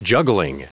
Prononciation audio / Fichier audio de JUGGLING en anglais
Prononciation du mot : juggling